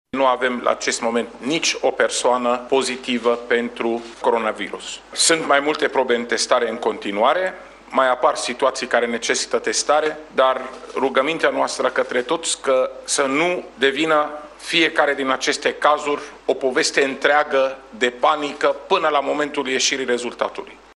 La rândul său, șeful Departamentului pentru Situaţii de Urgenţă, Raed Arafat a precizat că nu există motive de îngrijorare și de panică în ceeace privește situația îmbolnăvirilor cu coronavirus.